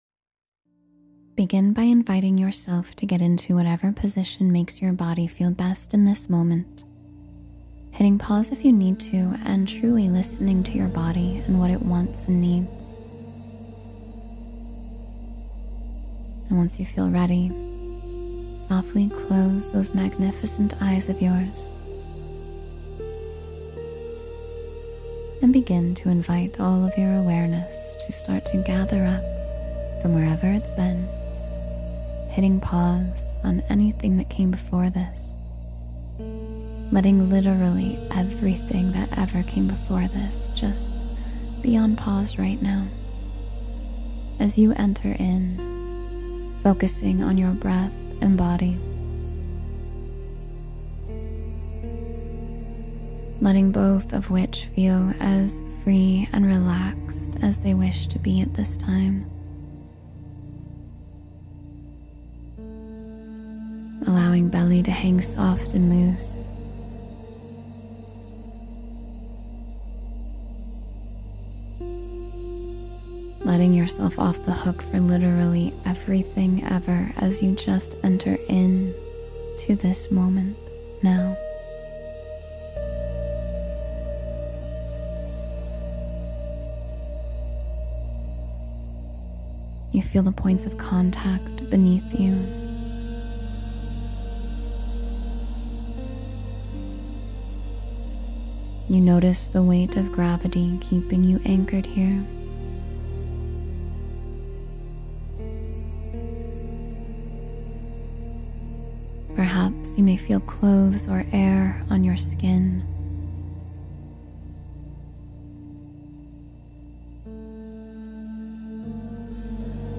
Morning Magic: Awaken Calm and Inner Joy — Meditation for a Positive Start